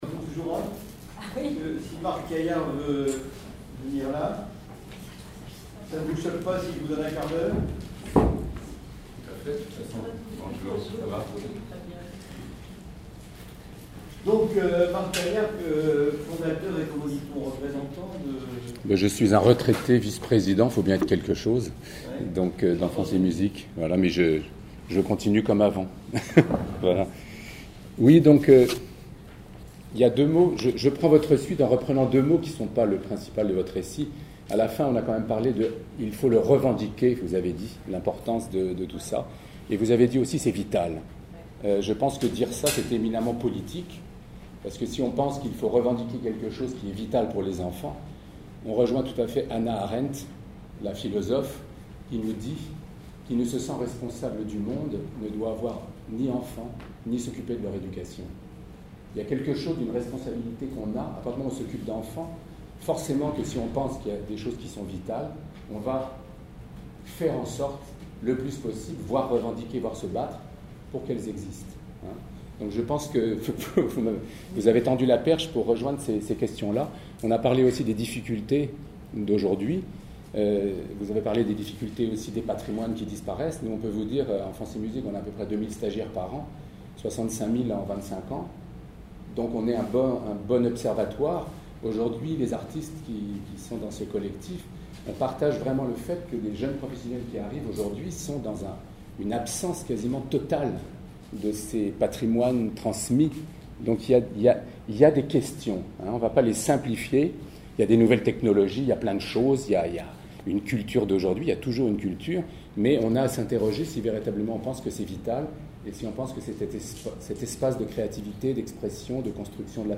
a été invité à présenter le nouveau protocole d’accord interministériel visant à promouvoir l’éveil artistique et culturel du très jeune enfant à l’occasion de la journée professionnelle du festival « Les Minuscules » le 20 juin 2017 à Villeneuve d’Ascq.
Enregistrement de l’intervention